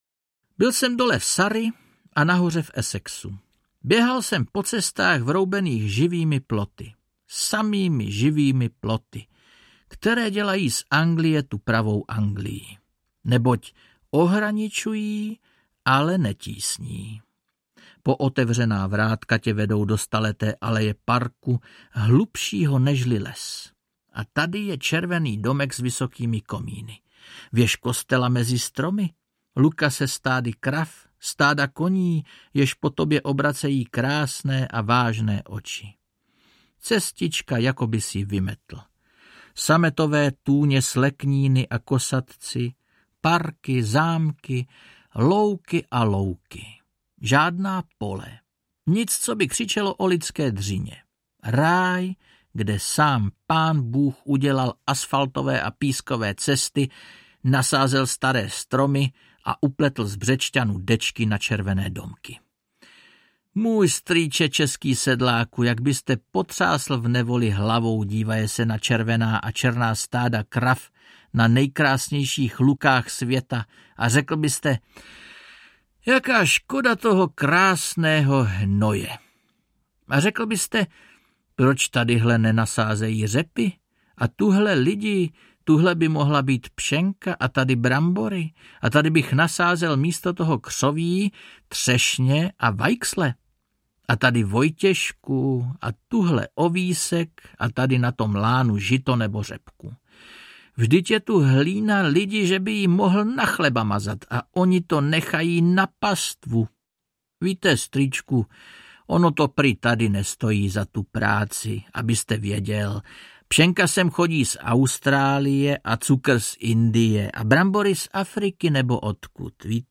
Anglické listy audiokniha
Ukázka z knihy
Vyrobilo studio Soundguru.